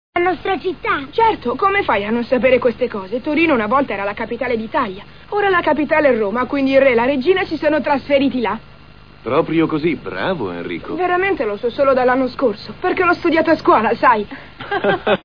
dal cartone animato "Cuore", in cui doppia Enrico.